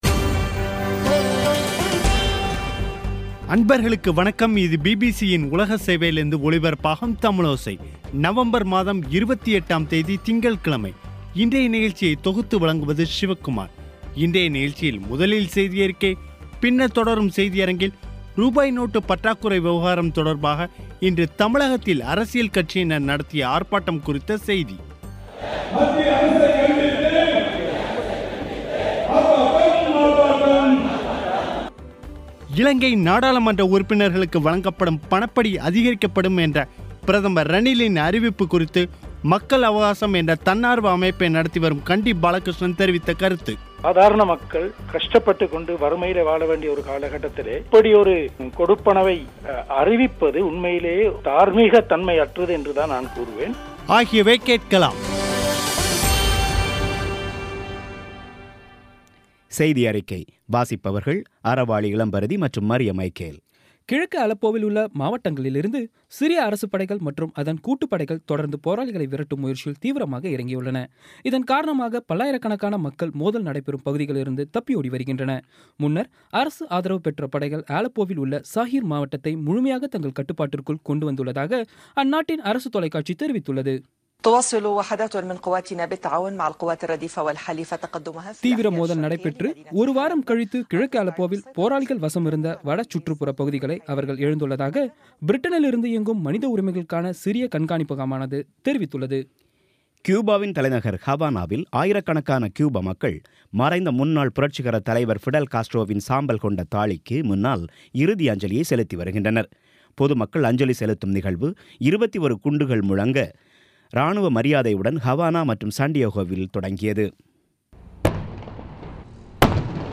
இன்றைய நிகழ்ச்சியில் முதலில் செய்தியறிக்கை, பின்னர் தொடரும் செய்தியரங்கில்